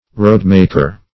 Roadmaker \Road"mak`er\, n. One who makes roads.